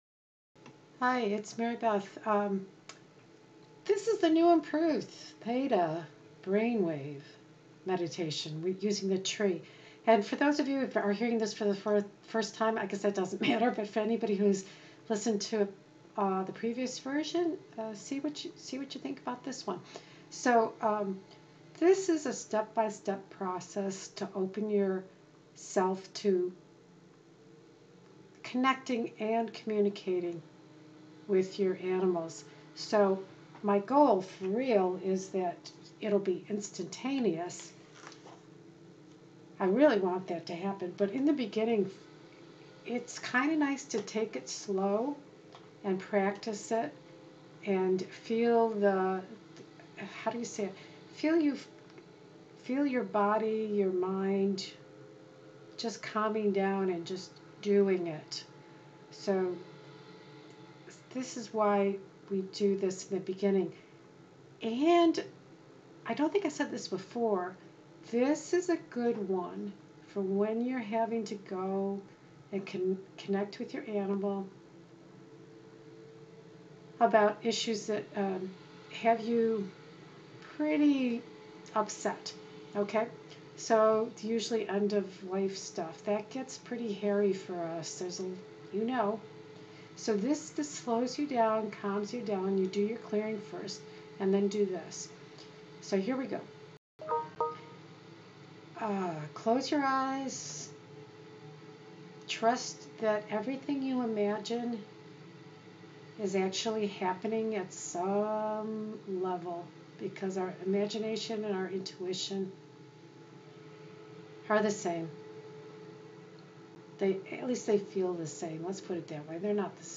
Tree Theta Brainwave Meditation
Theta-Tree-Connecting-Meditation.mp3